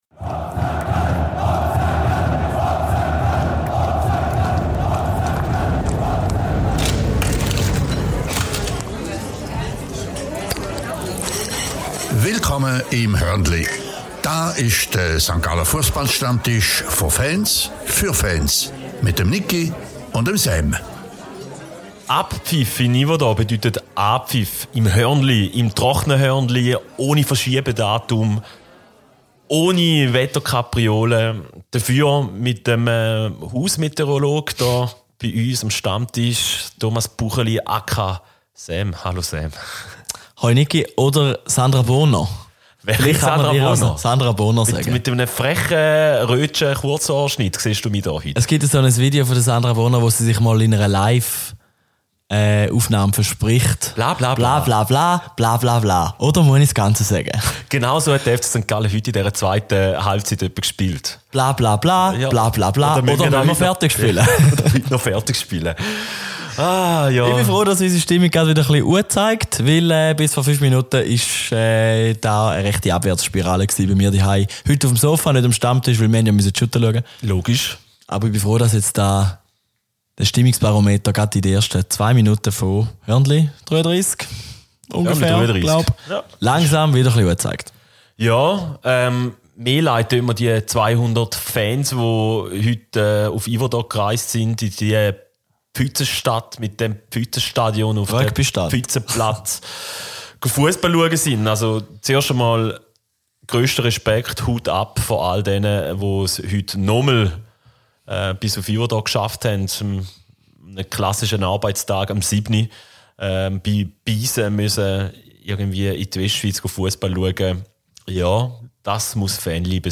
Vom Sofa treibt es die Hörnlis direkt an den Stammtisch. Entsprechend angefressen und emotionsgeladen sind die Jungs auch.